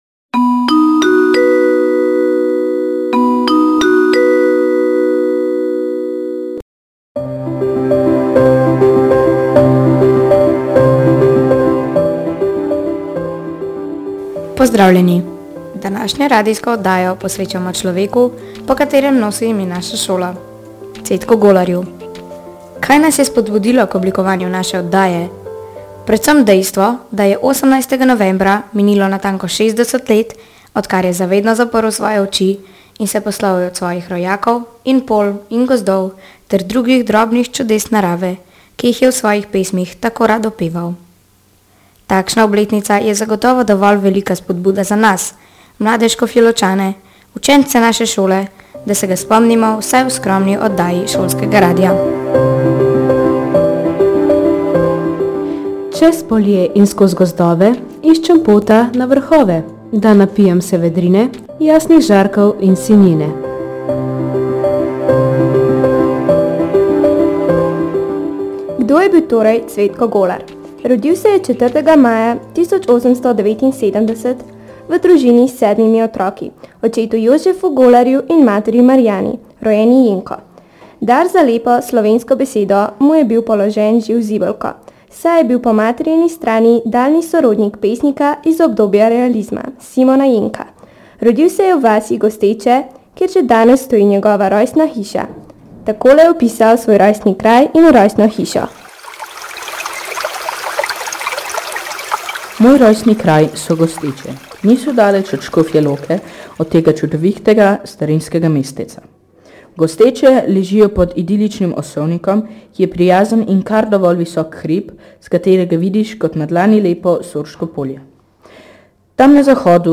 Radijska oddaja ob 60-letnici smrti Cvetka Golarja